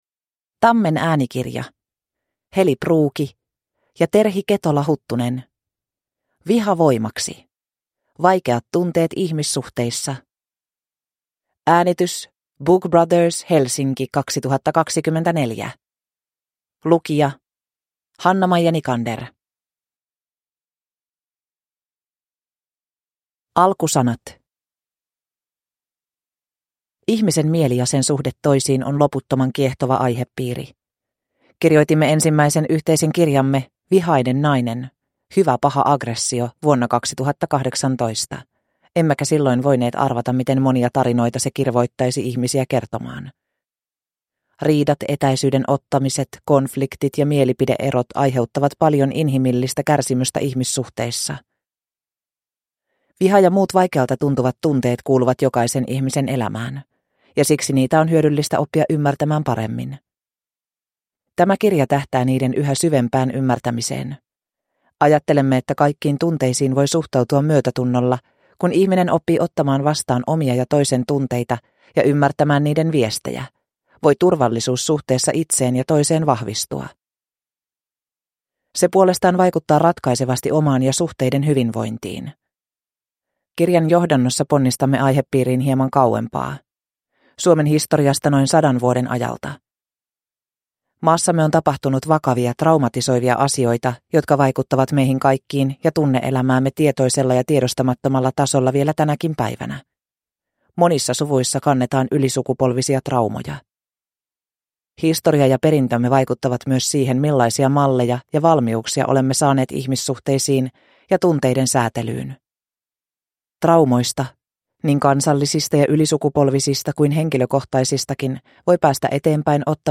Viha voimaksi – Ljudbok